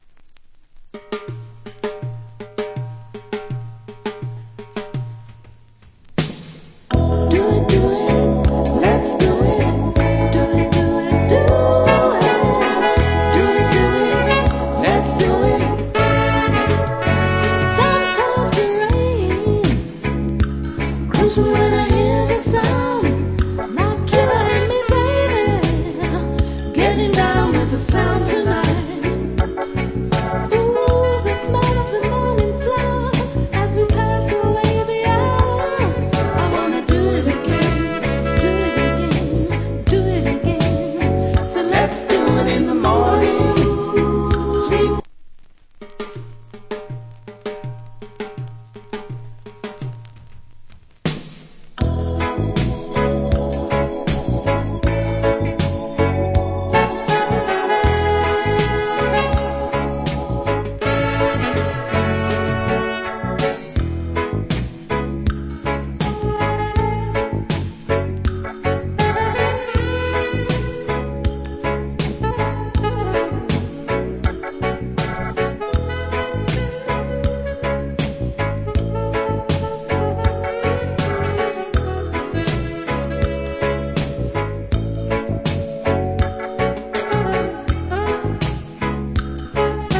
Early 80's. Nice Female Lovers Rock.